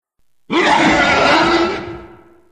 PLAY Loud Scream 1
scream-1_ZmRCStY.mp3